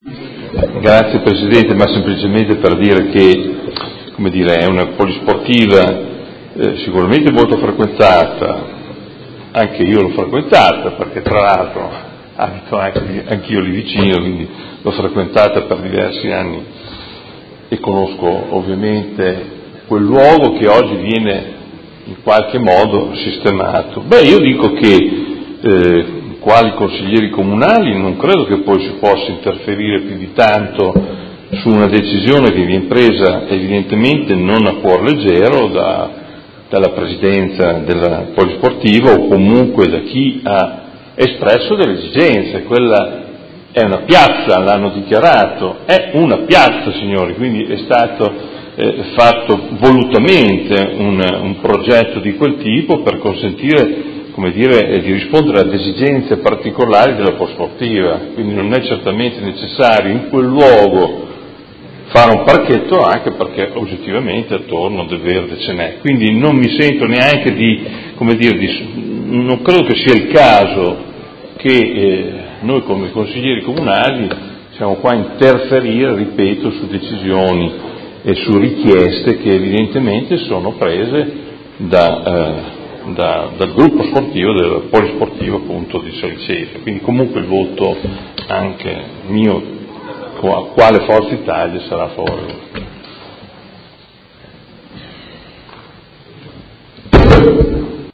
Adolfo Morandi — Sito Audio Consiglio Comunale